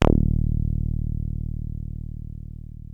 303 D#1 2.wav